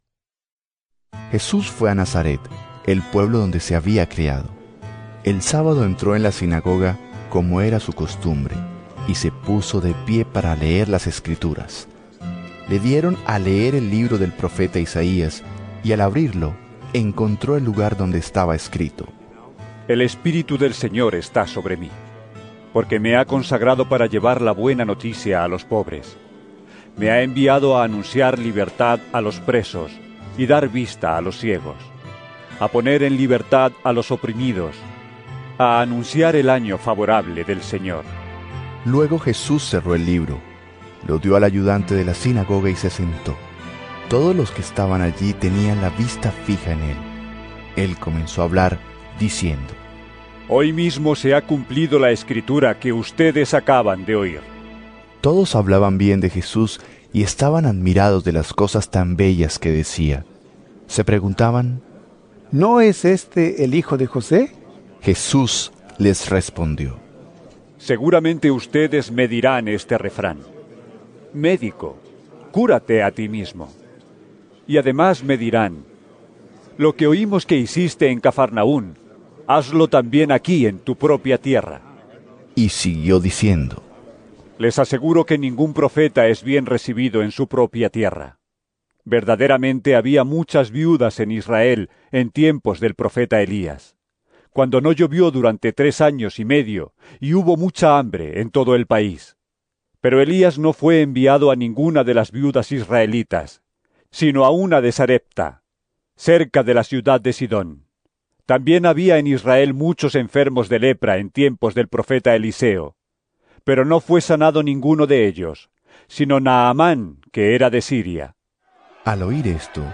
Lc 4 16-30 EVANGELIO EN AUDIO